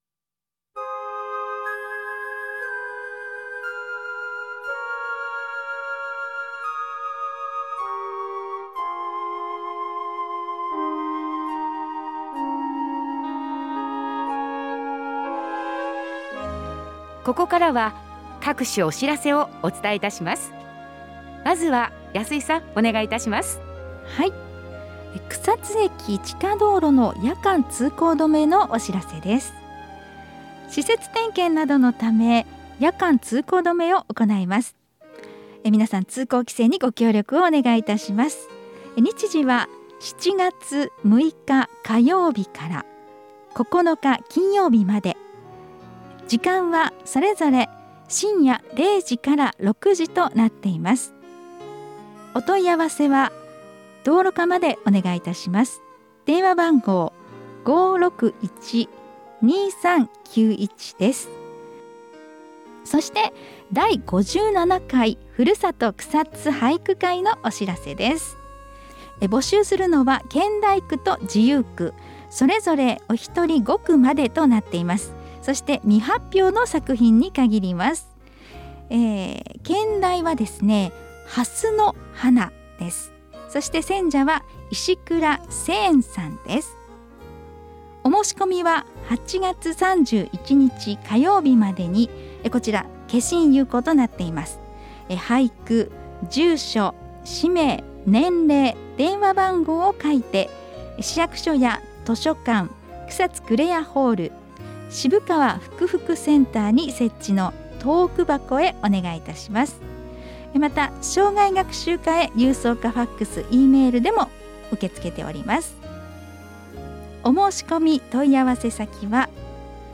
毎月発行される「広報くさつ」を、ＦＭラジオ放送でお届けしています。